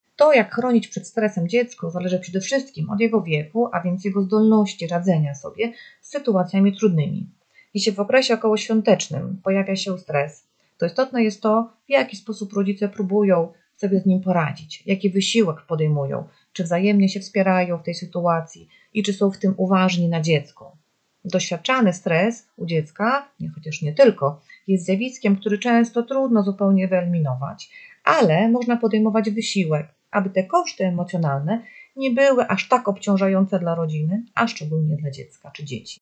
Rozmowa z psychologiem